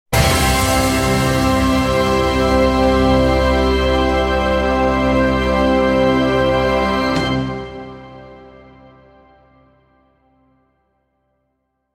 Epic Orchestral Final Hit Sound Effect
Powerful long orchestral hit sound effect, ideal for movie trailers, cinematic endings, video games, dramatic transitions, and epic final scenes.
Genres: Sound Logo Artist
Epic-orchestral-final-hit-sound-effect.mp3